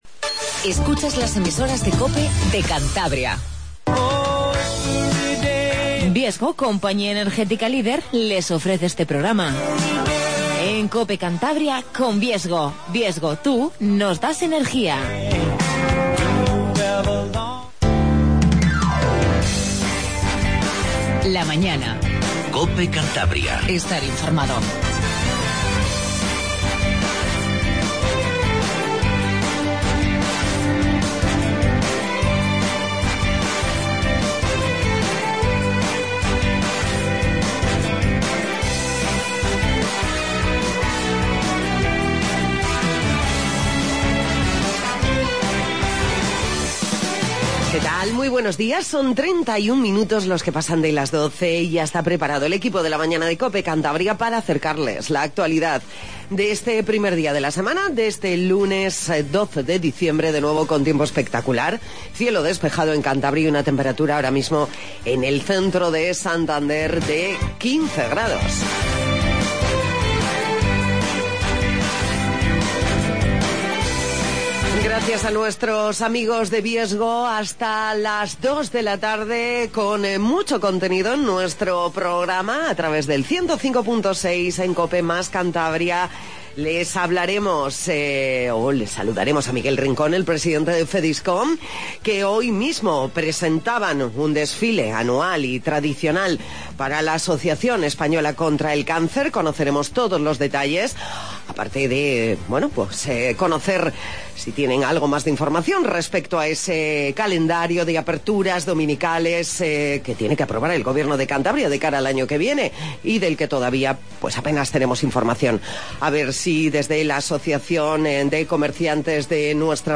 Magazine